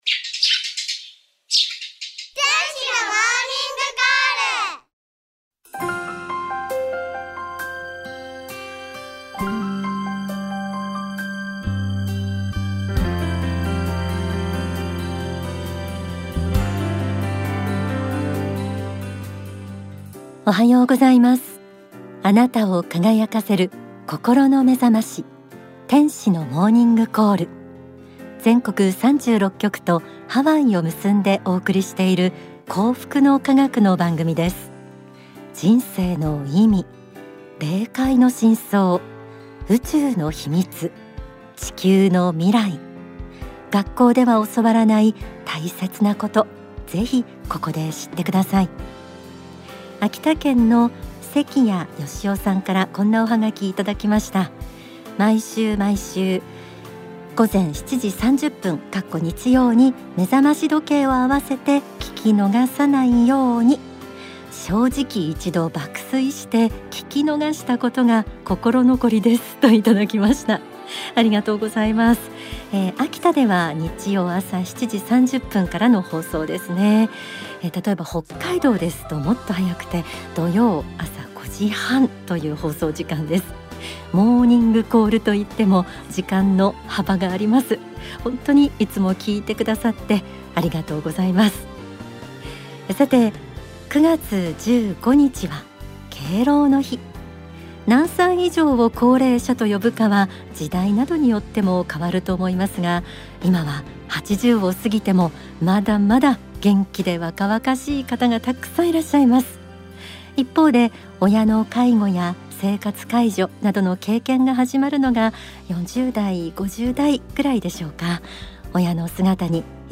番組後半には楽曲「永遠の青春」